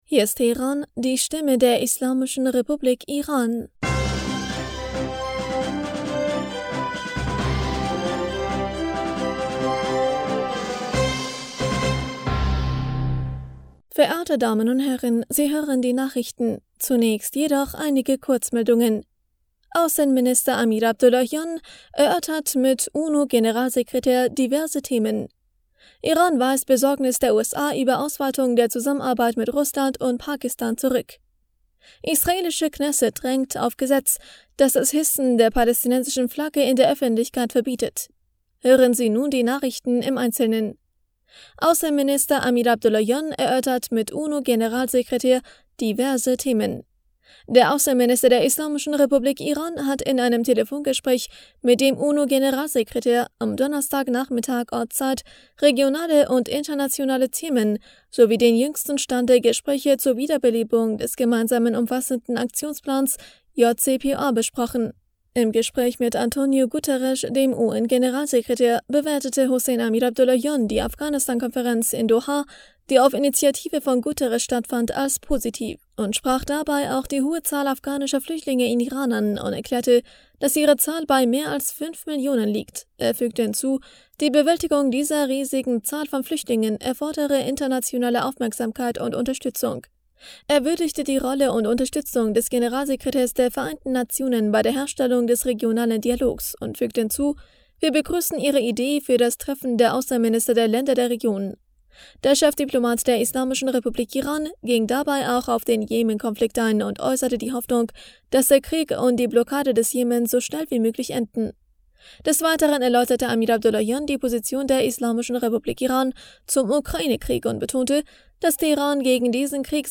Nachrichten vom 19. Mai 2023